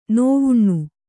♪ nōvuṇṇu